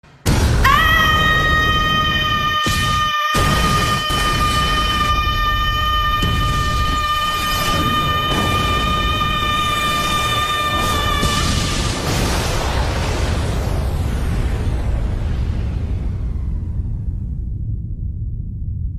Here is the Sound Button for Ahh that you can use for making hilarious memes and editing
Ahh